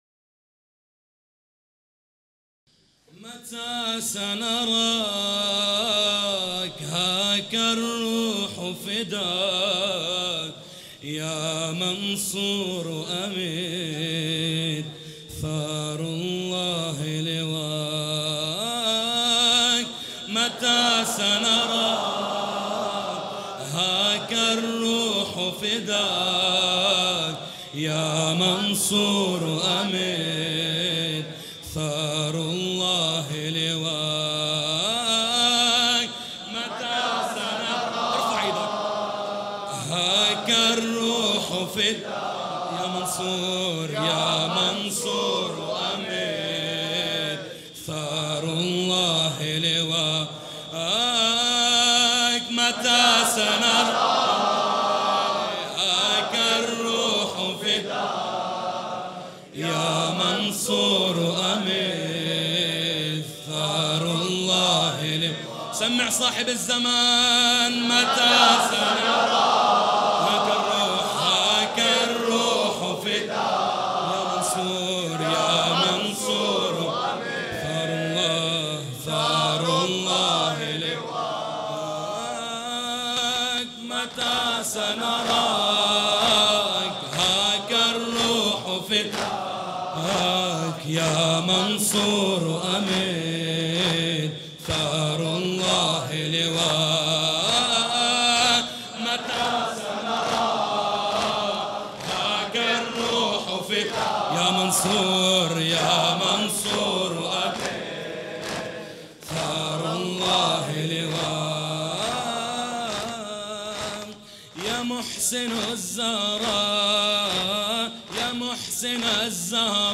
زمینه (عربی) | متی سنراک ها روح فداک یا منصور امیر ثارالله لواک
مداحی
مراسم عزاداری شهادت حضرت زهرا(س)